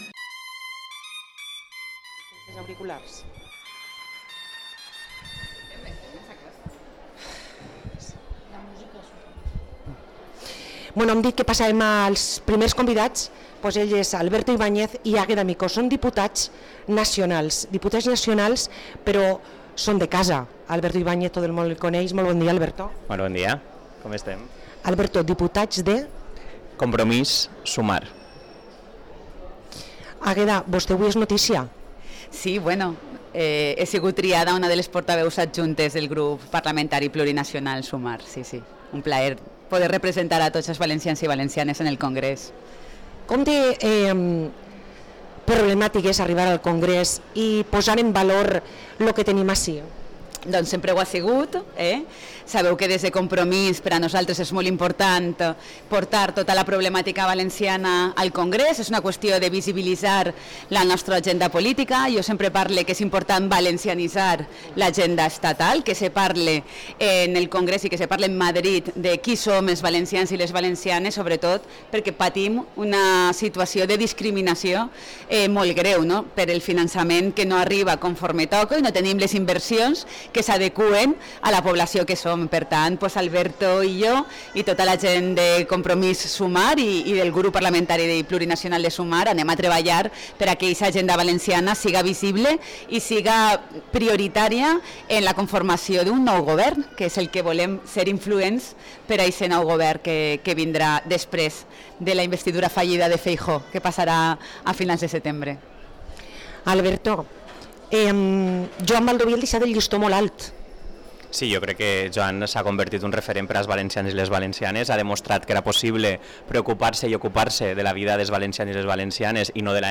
Hui ens visiten Alberto Ibañez i Àgueda Micó, Diputats per Compromís-Sumar